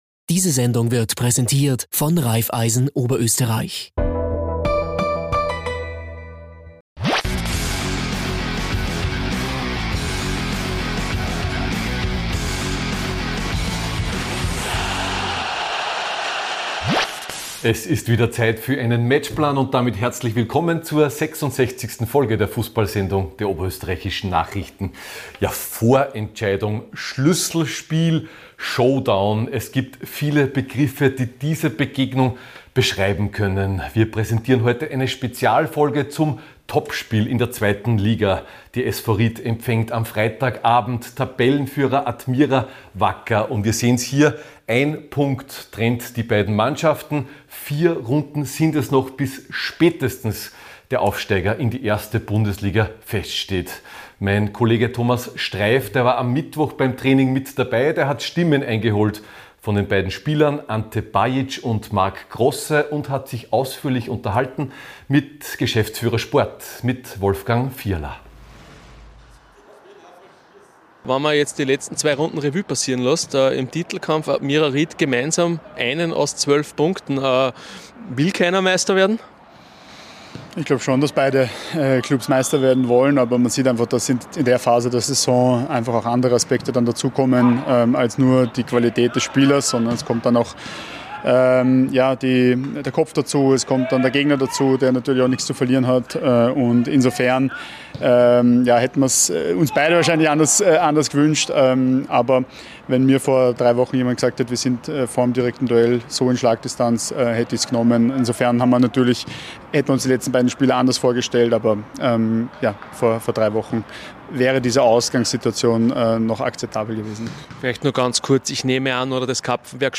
Lagebericht